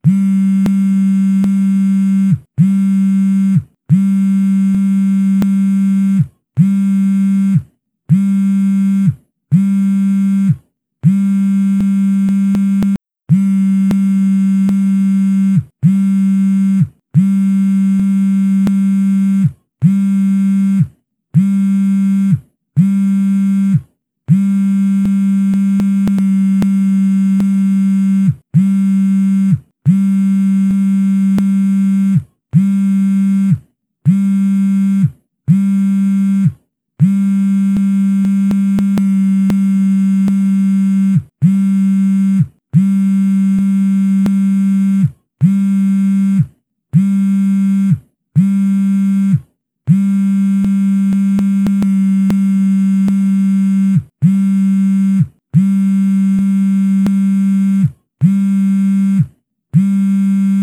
vibrate.mp3